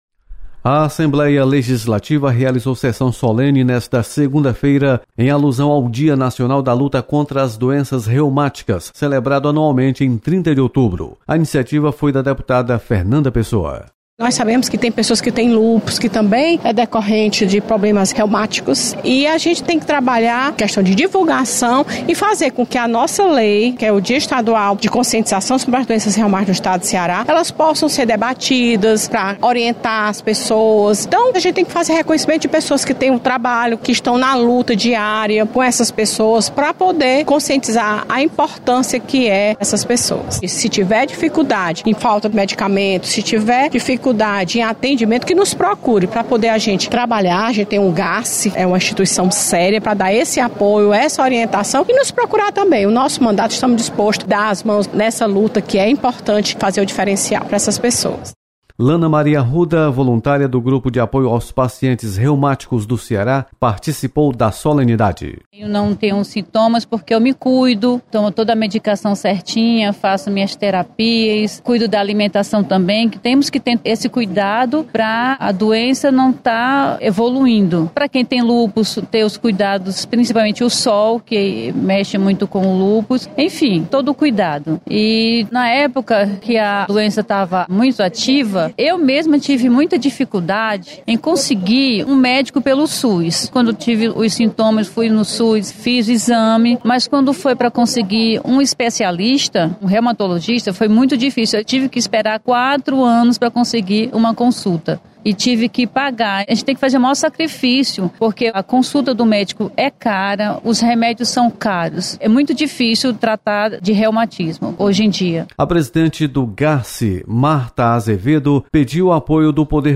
Sessão solene celebra o Dia Nacional da Luta contra as Doenças Reumáticas. Repórter